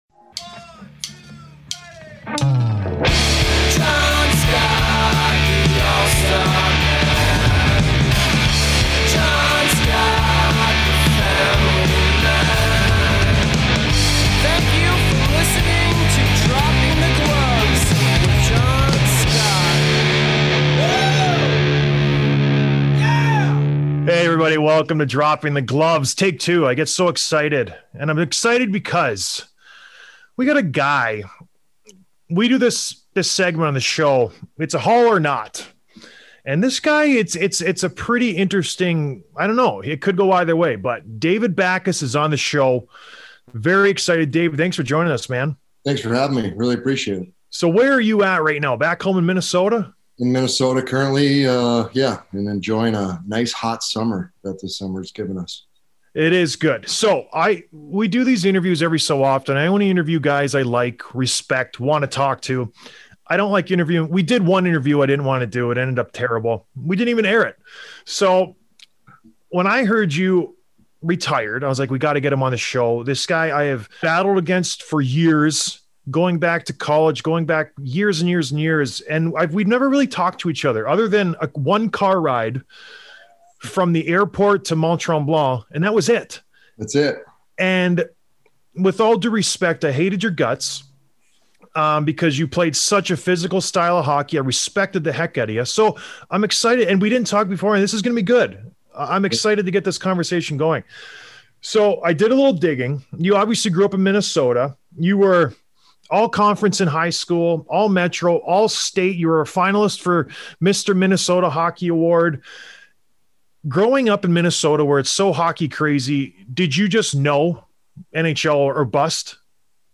Interview with David Backes